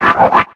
infinitefusion-e18/Audio/SE/Cries/NUMEL.ogg at a50151c4af7b086115dea36392b4bdbb65a07231